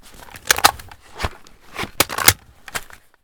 vihr_reload.ogg